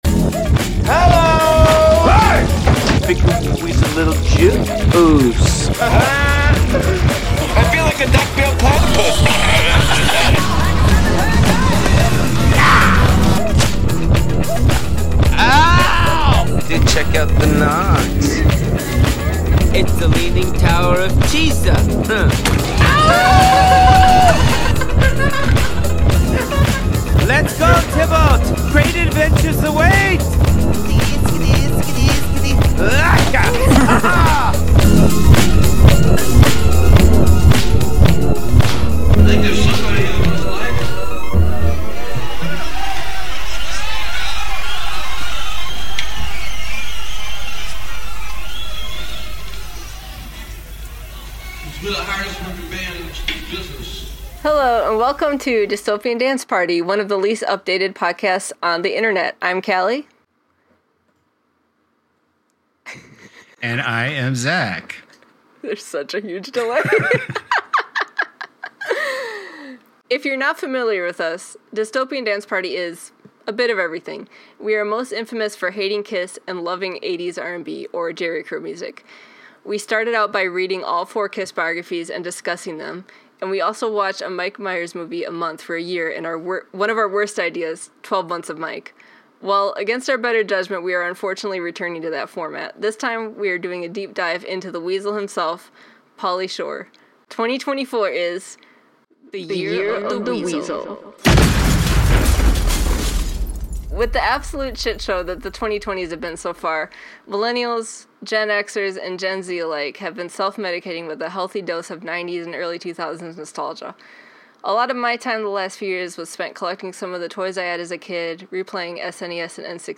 We will troubleshoot this moving forward, but in the meantime, enjoy watching our audio swim in and out of sync while the video footage struggles to catch up with our lips!